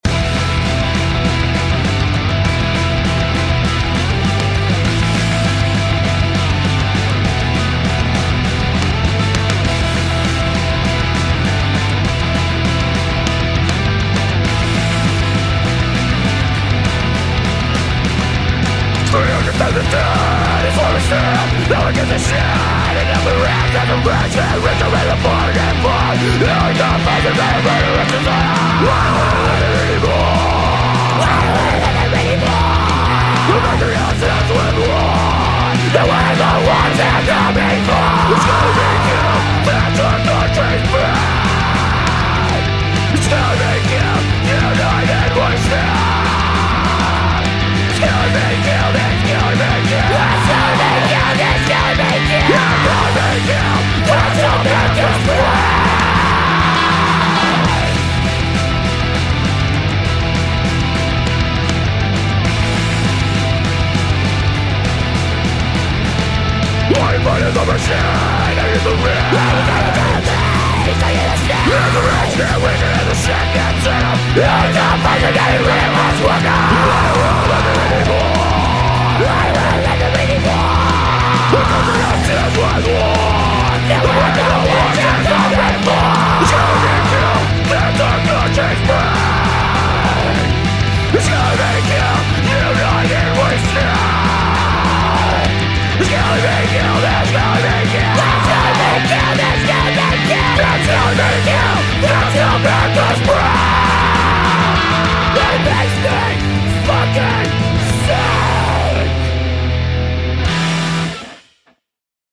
“Kill or Be Killed” by MötorCrüsher, and you will hear three different people singing. Hell, the MötorCrüsher record has at least five different voices.